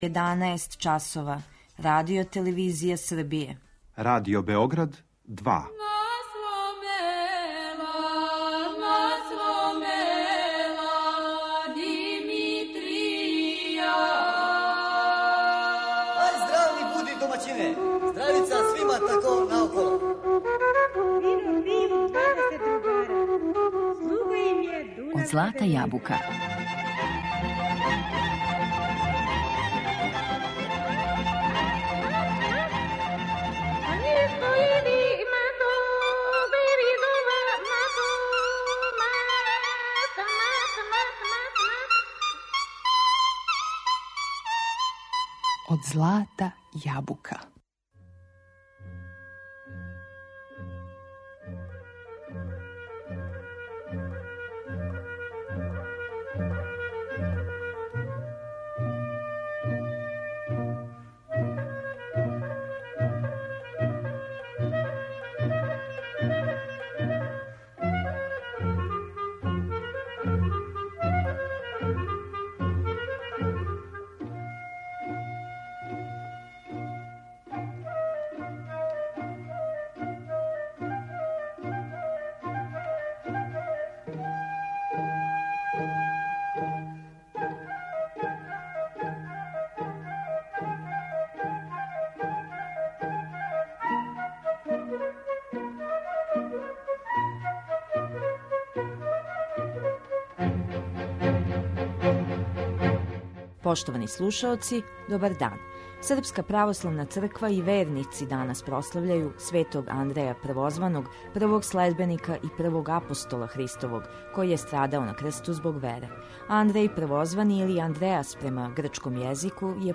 У данашњој емисији Од злата јабука слушамо снимке Великог народног оркестра, две нумере тамбурашког оркестра РТВ Нови Сад и групе певача.